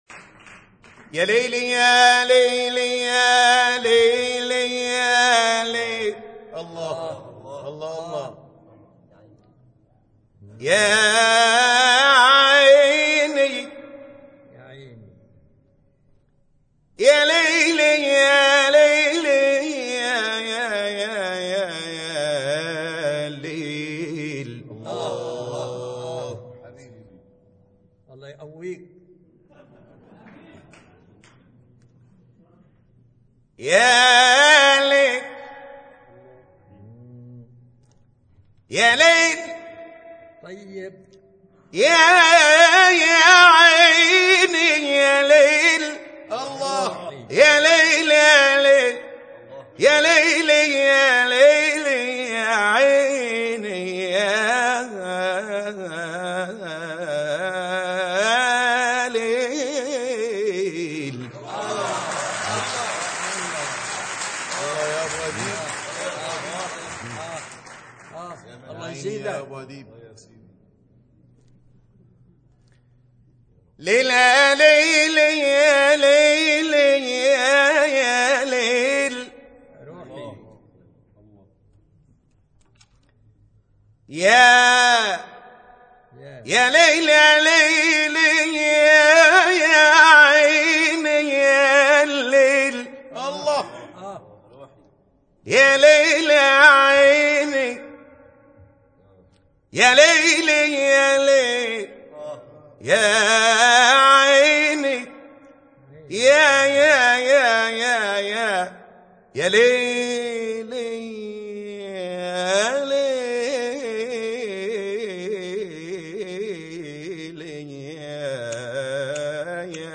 أناشيد اسلامية